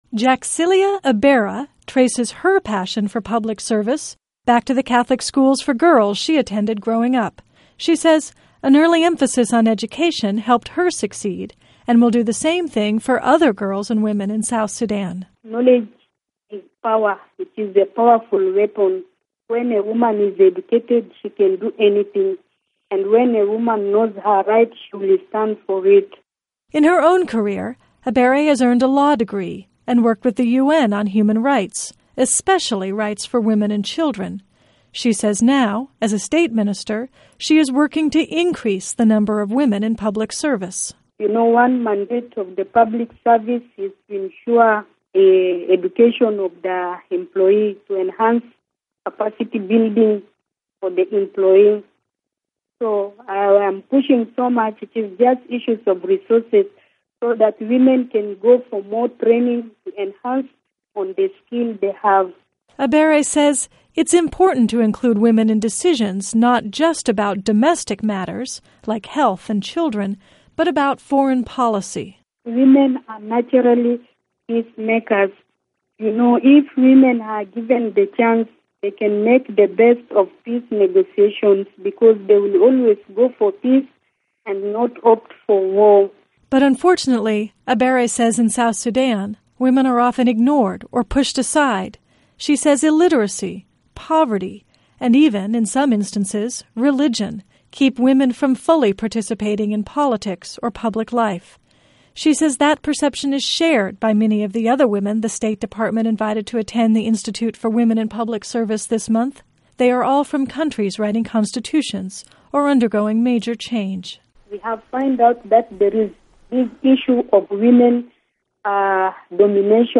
One of South Sudan's few female state lawmakers speaks from Wellesley College's new Institute for Women in Public Service